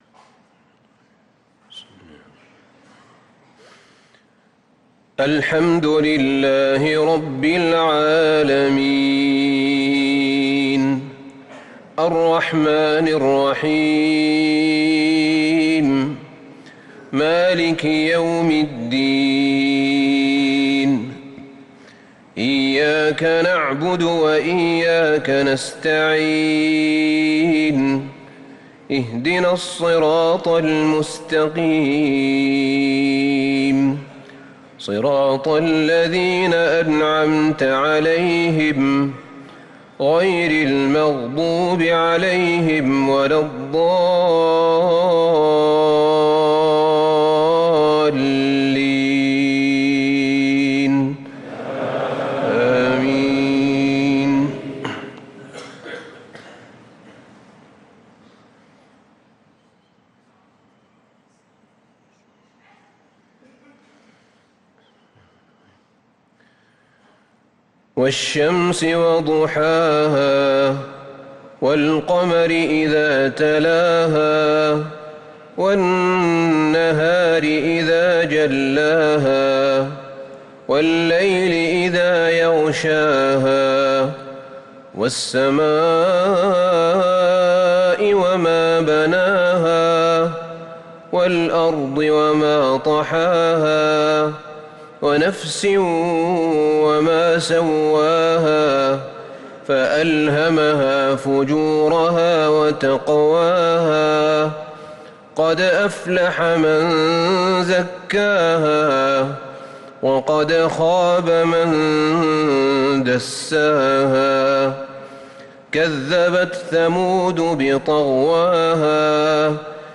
صلاة العشاء للقارئ أحمد بن طالب حميد 16 جمادي الأول 1445 هـ
تِلَاوَات الْحَرَمَيْن .